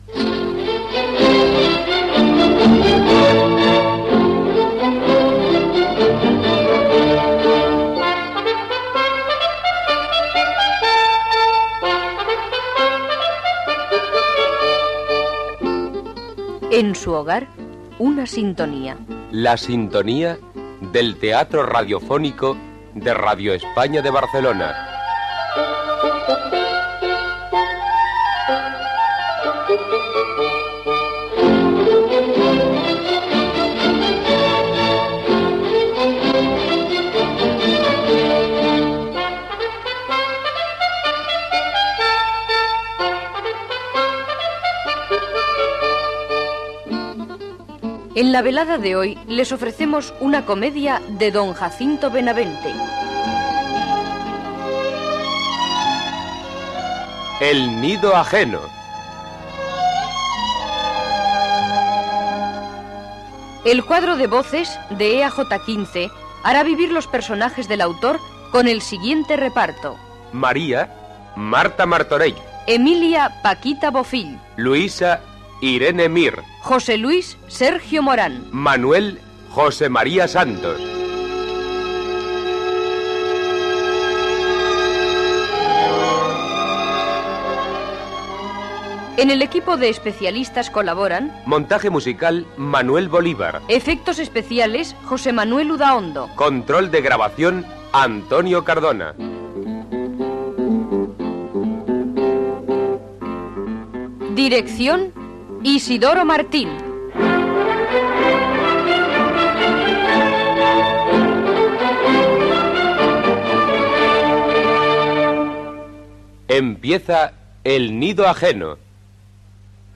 Adaptació de l'obra "El nido ajeno" (1894) de Jacinto Benavente. Careta del programa, amb el repartiment i primeres escenes
Ficció